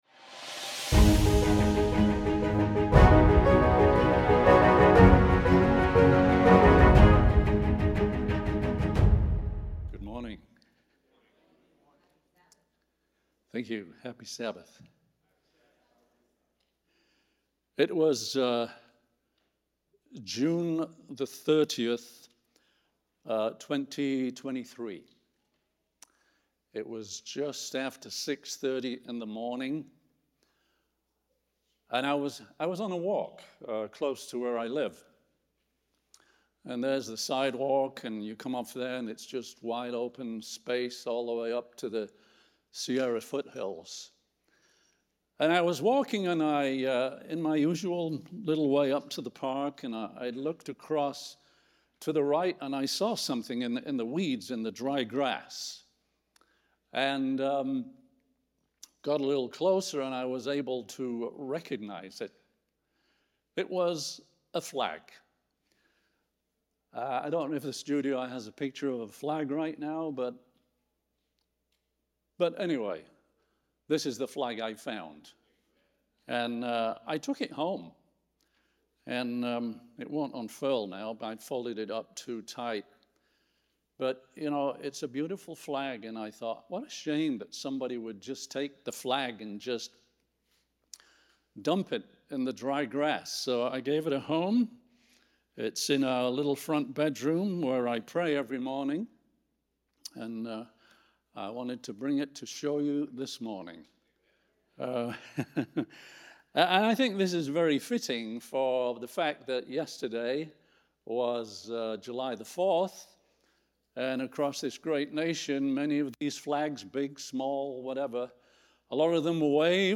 Sermon Archive – Sacramento Central Seventh-day Adventist Church